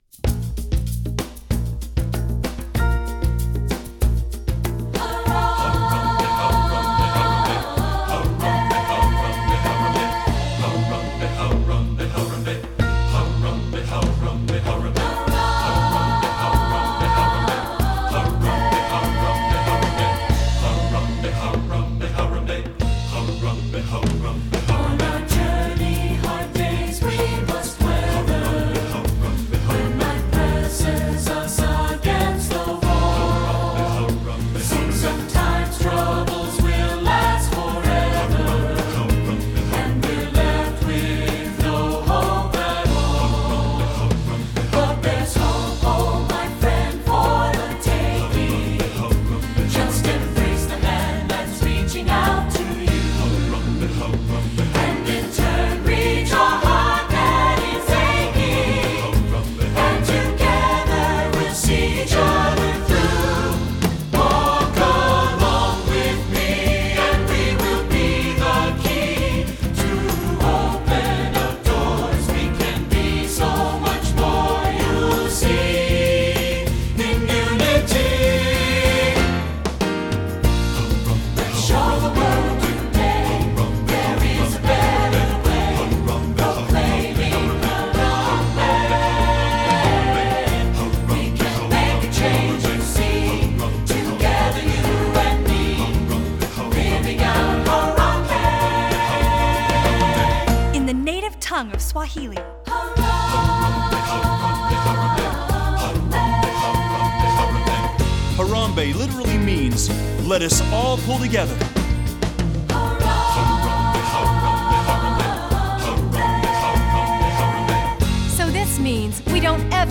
Voicing: SSAB